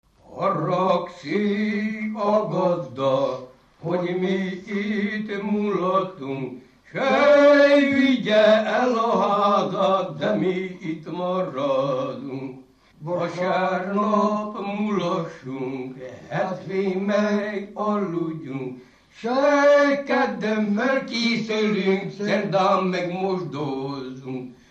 Dunántúl - Somogy vm. - Hedrehely
ének
Stílus: 5. Rákóczi dallamkör és fríg környezete
Kadencia: 1 (1) 4 1